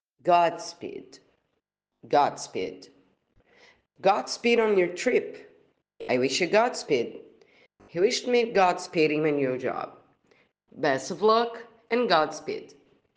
PRONUNCIATION 🗣